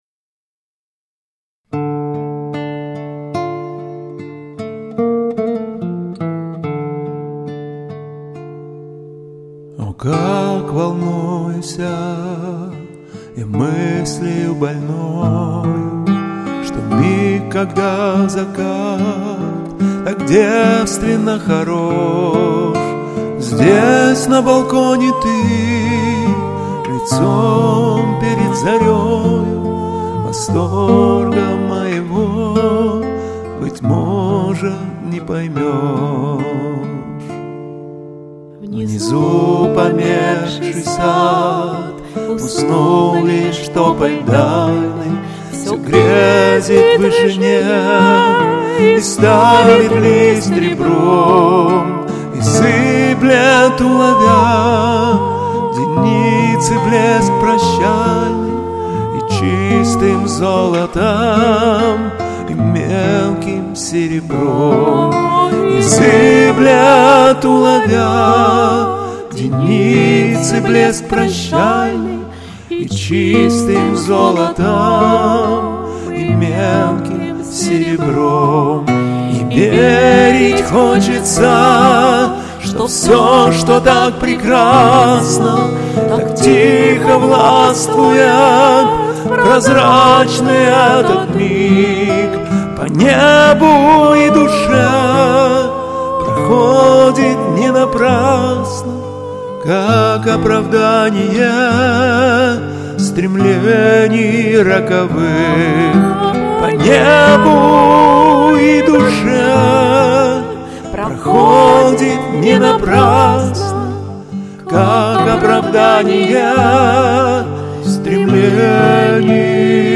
поющий в жанре лирической песни